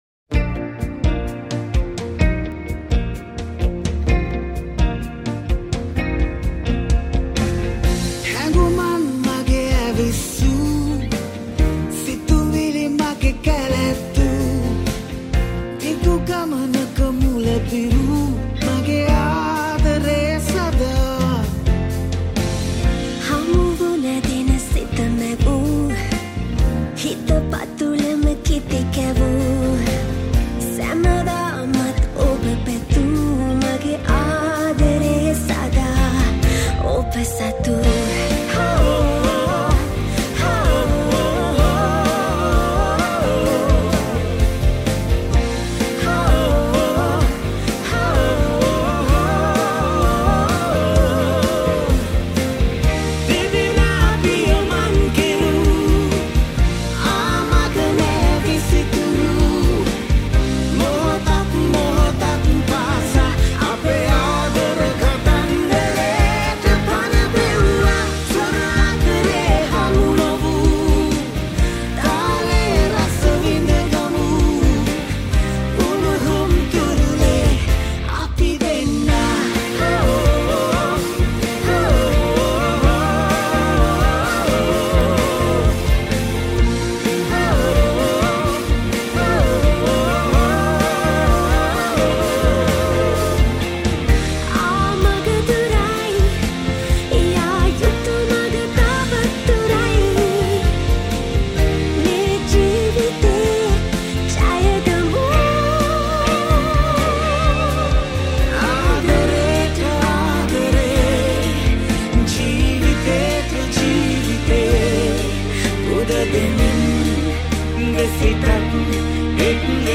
Piano & Keys